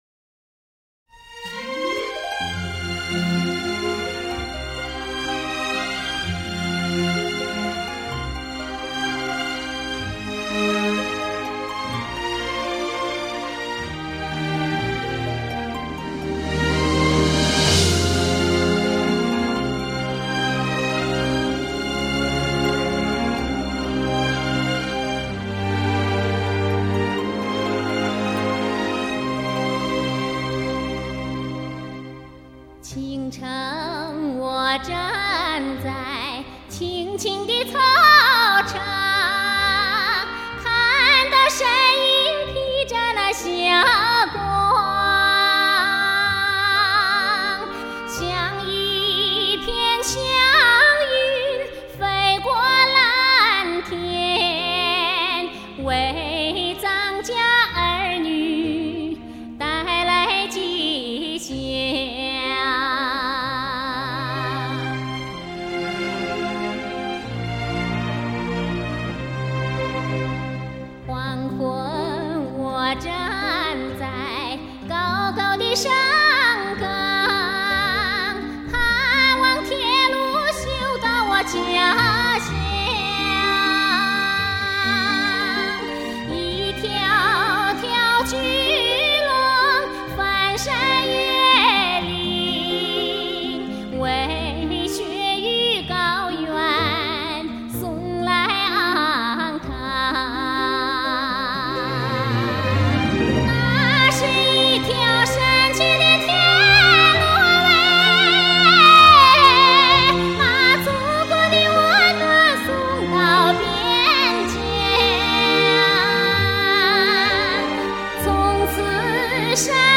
来自雪域的第一天籁女声，浸人肺腑，唯美空灵，全新演绎、藏韵天音、美不胜收！